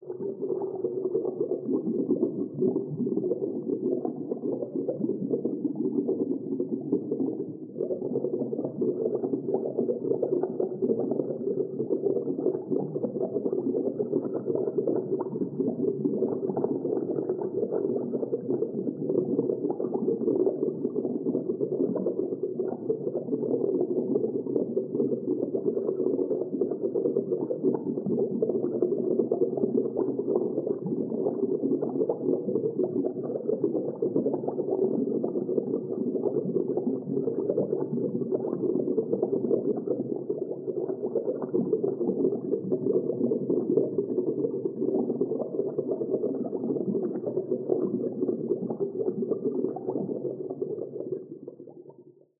Dive Deep - Small Bubbles 04.wav